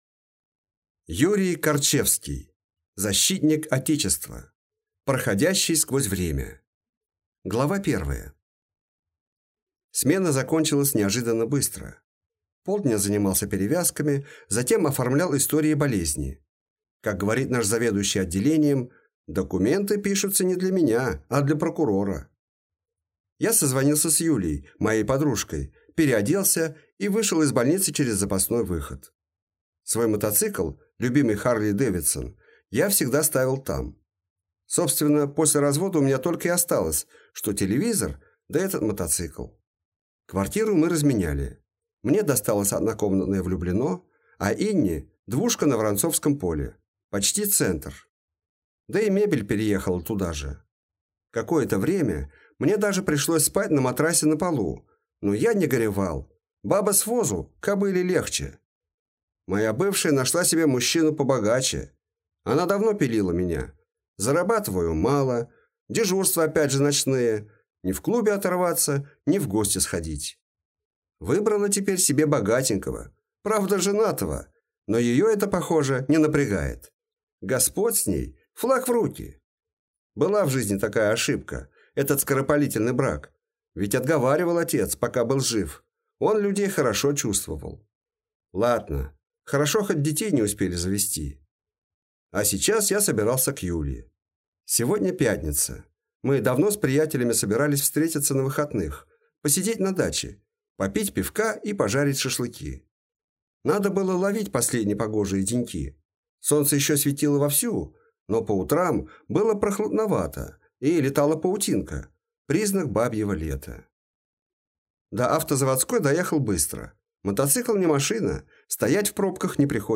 Аудиокнига Защитник Отечества. Проходящий сквозь время | Библиотека аудиокниг